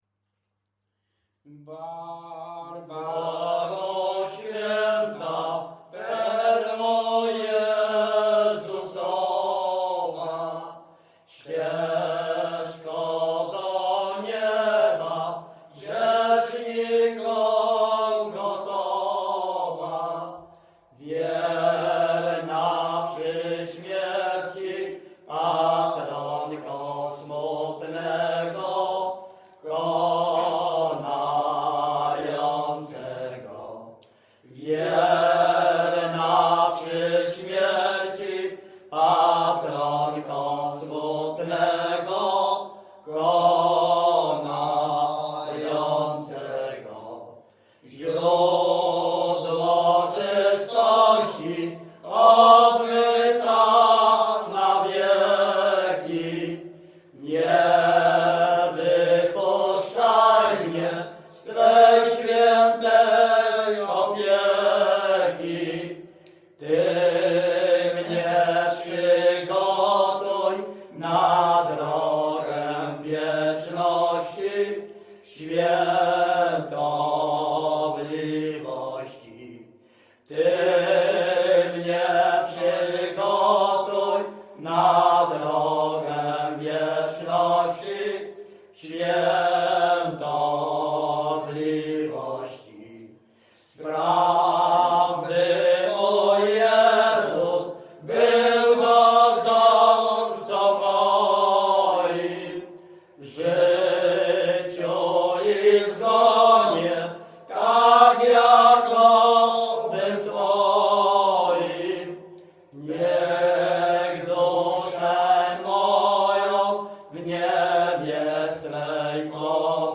Śpiewacy z Ruszkowa Pierwszego
Wielkopolska, powiat kolski, gmina Kościelec, wieś Ruszków Pierwszy
Pogrzebowa
pogrzebowe nabożne katolickie do grobu o świętych